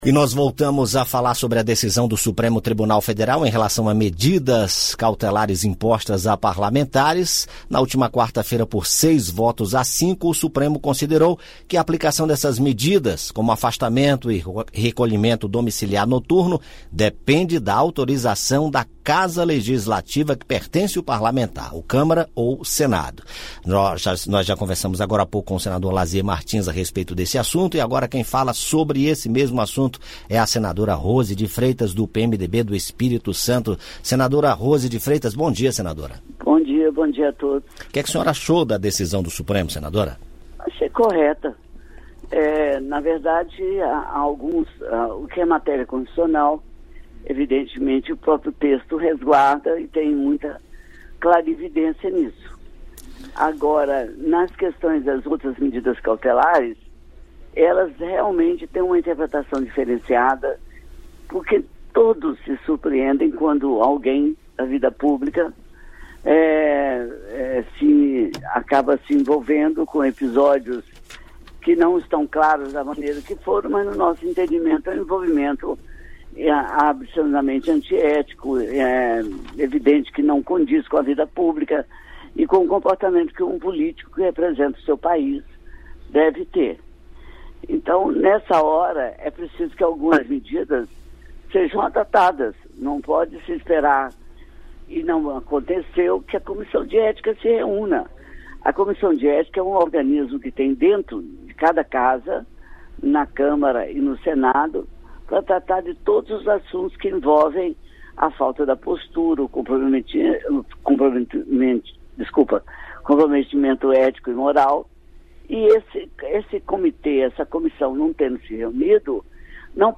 A senadora Rose de Freitas (PMDB-ES) afirmou, em entrevista à Rádio Senado, que concorda com a decisão do Supremo Tribunal Federal (STF) sobre medidas cautelares previstas no Código de Processo Penal (CPP) e impostas a parlamentares. Na opinião da senadora, medidas cautelares como afastamento do mandato e recolhimento domiliciar noturno podem ser aplicadas a parlamentares, mas considerou "correta" a posição do STF de que a aplicação delas depende de autorização do Senado ou da Câmara.